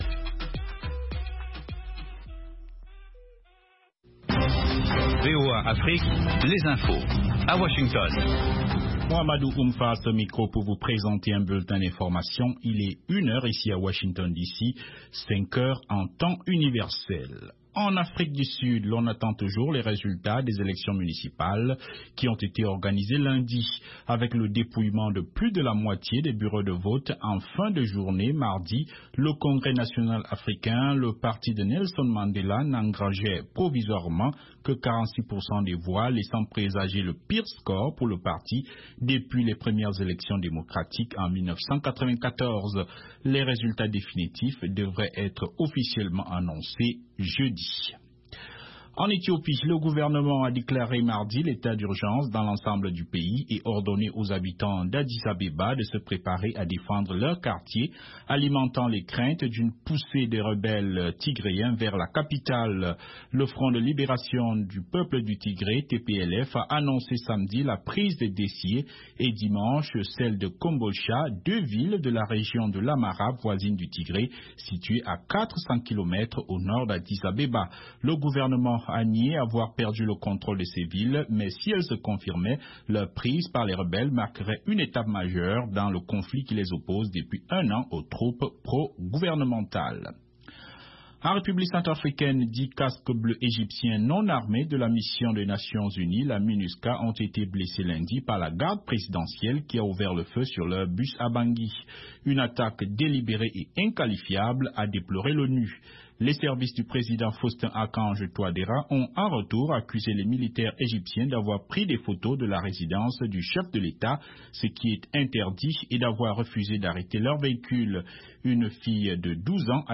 Bulletin
5min Newscast